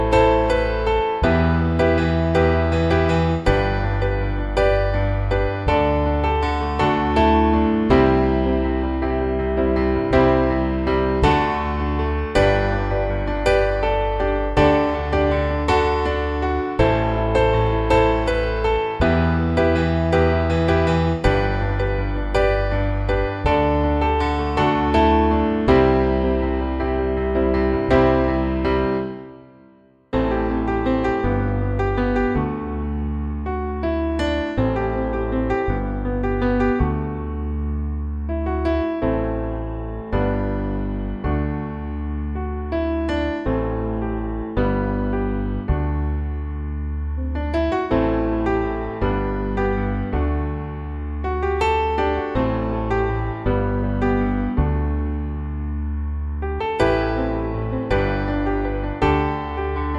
Five Semitones Up Pop (2010s) 4:04 Buy £1.50